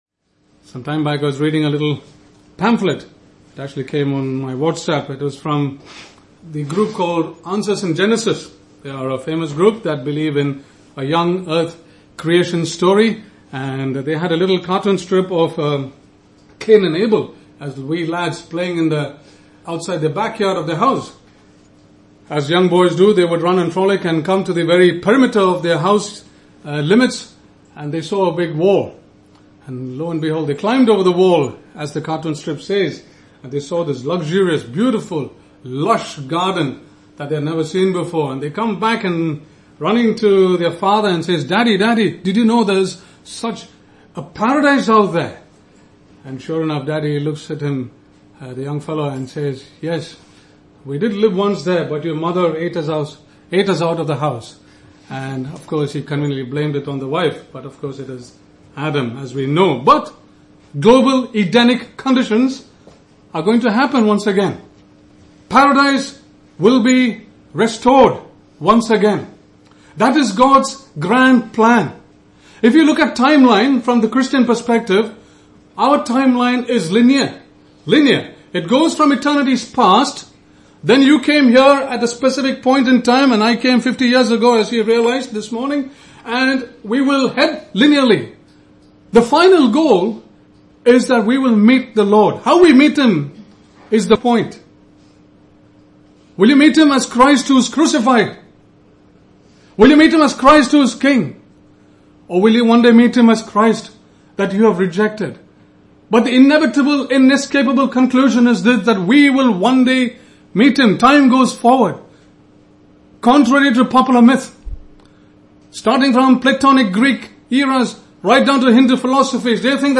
(Message preached 31st Mar 2019)